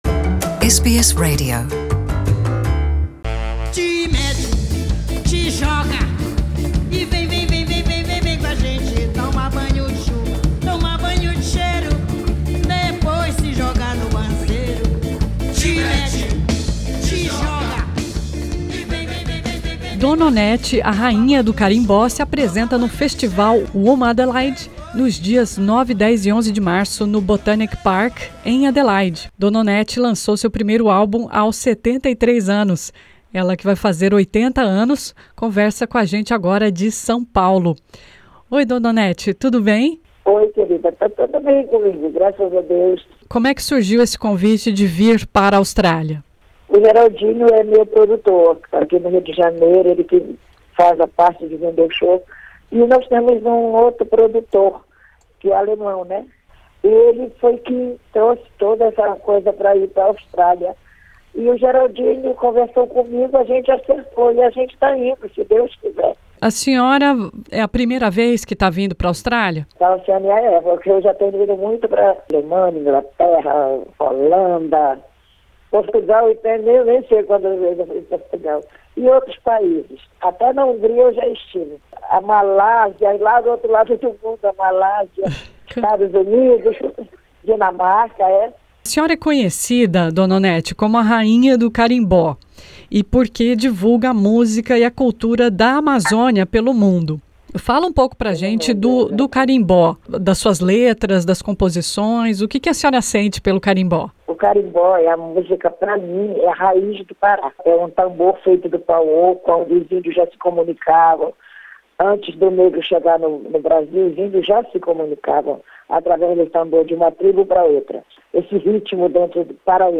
Nessa entrevista ela fala do início da carreira profissional aos 70 anos de idade (Dona Onete faz 80 em junho).